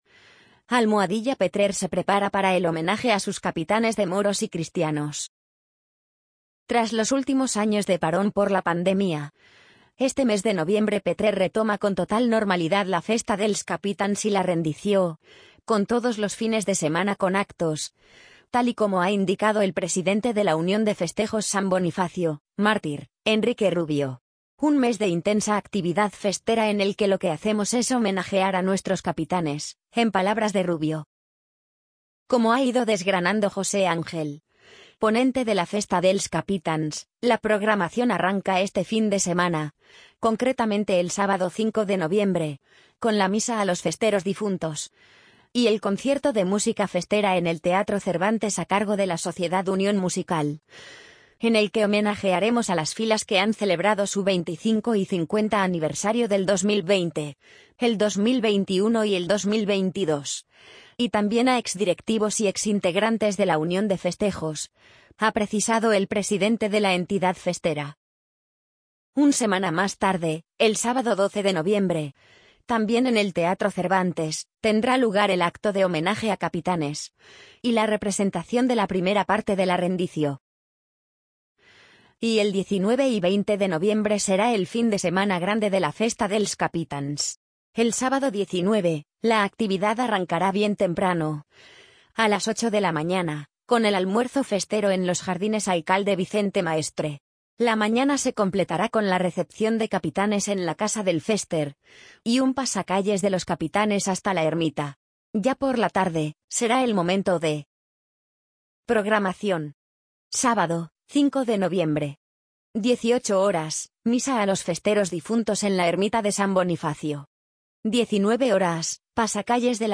amazon_polly_61479.mp3